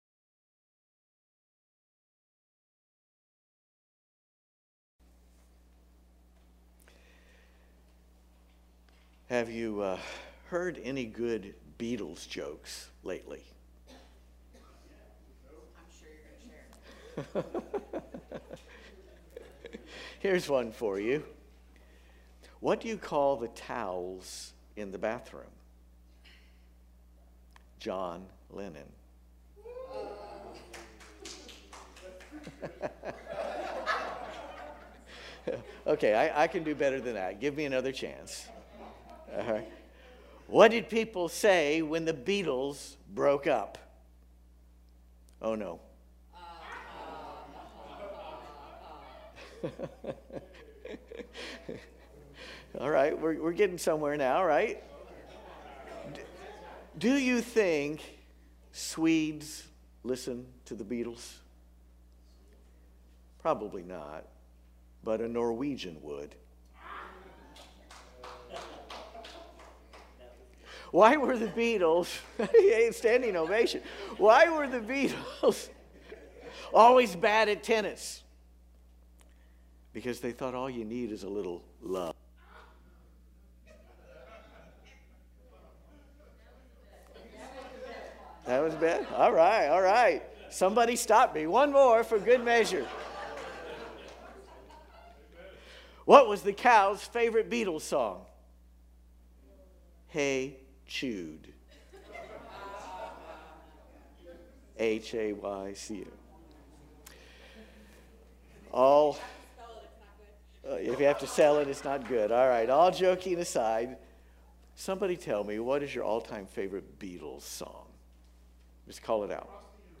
LIVE Stream Replay